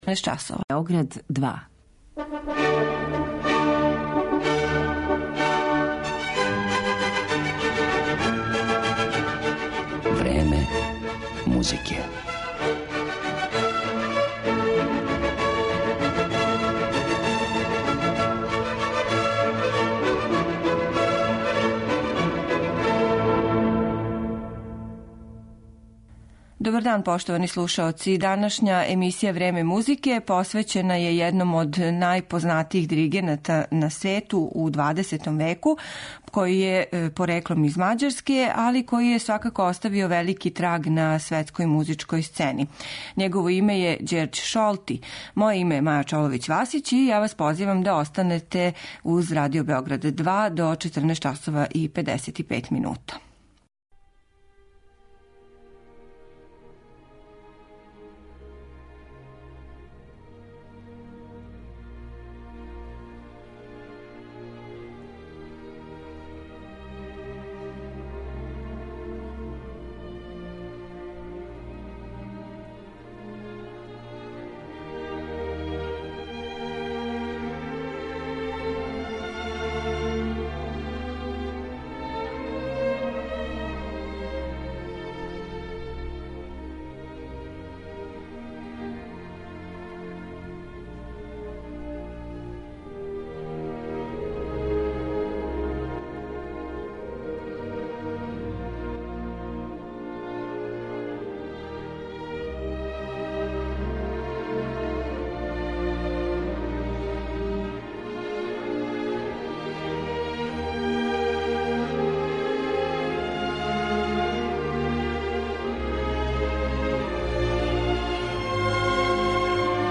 Његов портрет осликаћемо музиком Чајковског, Малера, Елгара, Вагнера и Бартока.